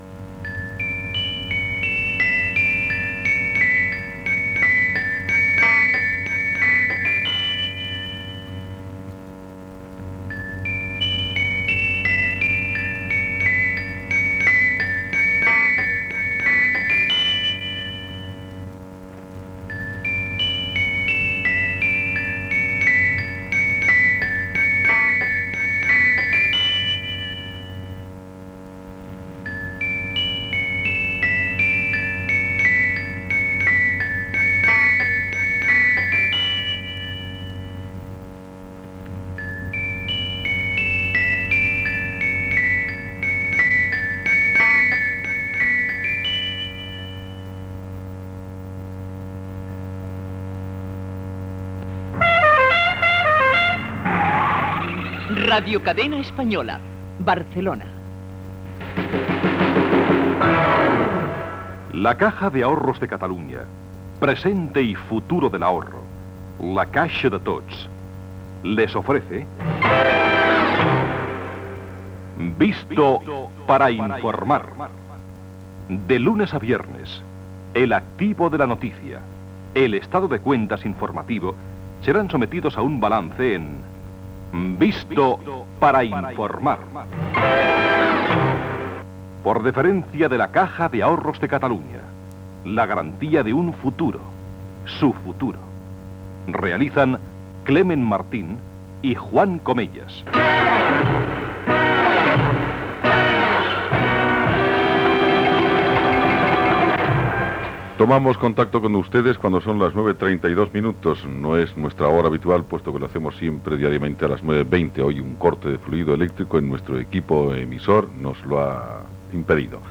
Sintonia de l'emissora, indicatiu i inici del programa, després d'un tall en l'emissió.
Informatiu